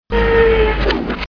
Elevator down
Category: Sound FX   Right: Personal
Tags: Elevator Sounds Elevator Elevator Sound clips Elevator sound Sound effect